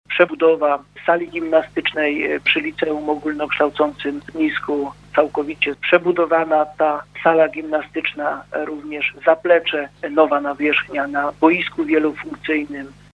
Mówi starosta niżański Robert Bednarz: